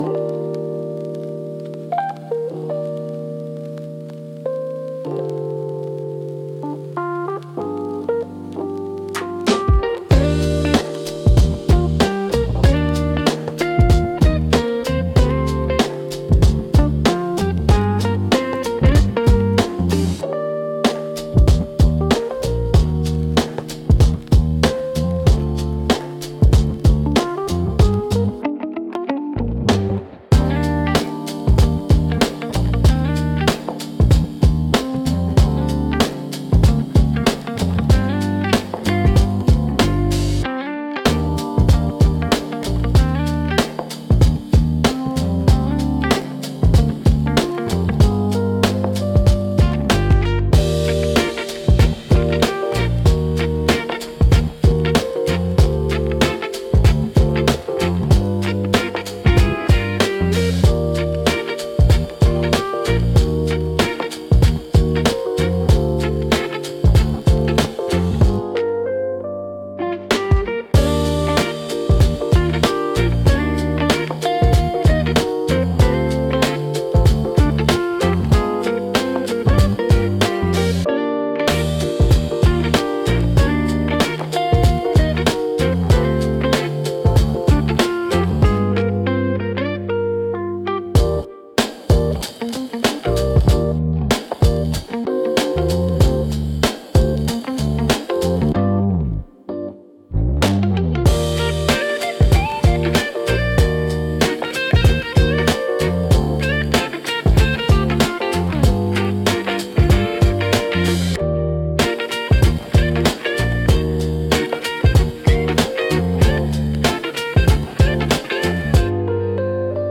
Relaxing Lo-Fi Flow